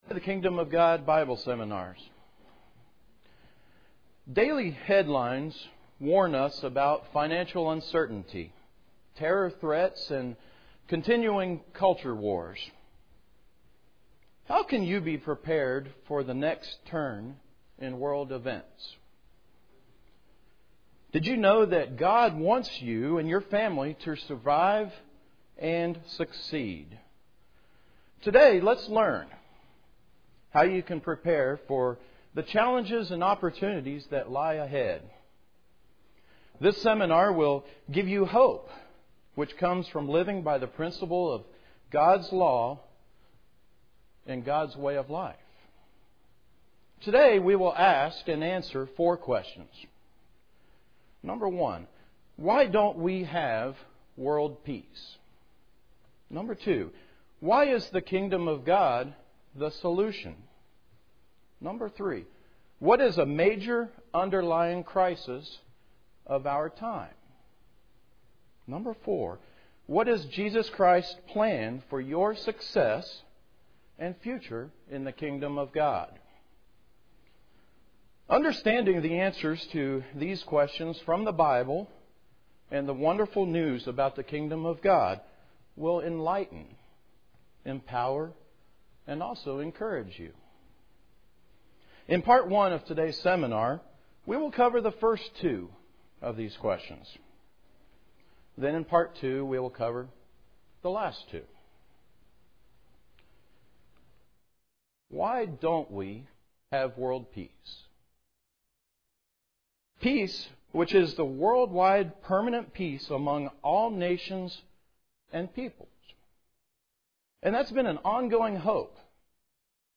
Given in Charlotte, NC
UCG Sermon Studying the bible?